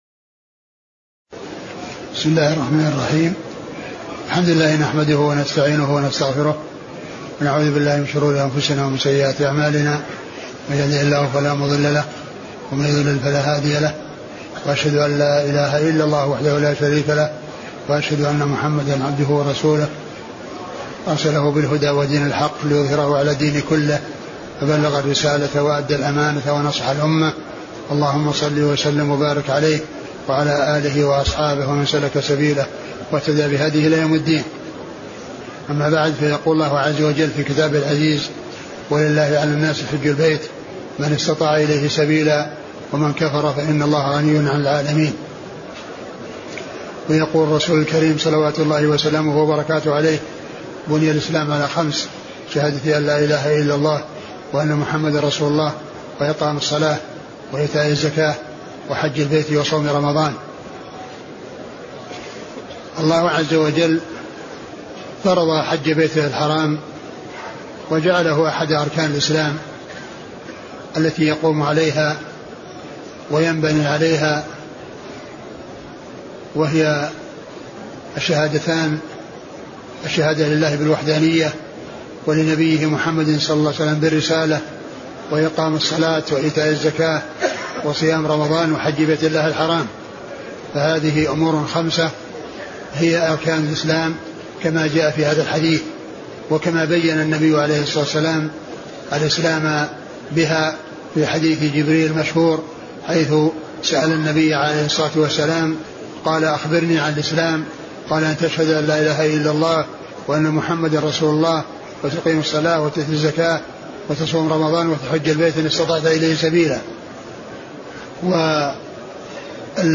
محاضرة فضل الحج
تاريخ النشر ٢١ ذو القعدة ١٤٢٧ المكان: المسجد النبوي الشيخ: فضيلة الشيخ عبدالمحسن بن حمد العباد البدر فضيلة الشيخ عبدالمحسن بن حمد العباد البدر فضل الحج